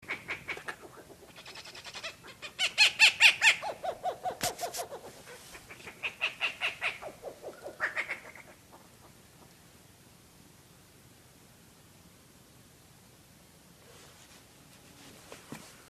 Rufous-legged Owl (Strix rufipes)
Sex: Both
Life Stage: Adult
Location or protected area: San Carlos de Bariloche
Condition: Wild
Certainty: Photographed, Recorded vocal
Lechuza-Bataraz-Austral.mp3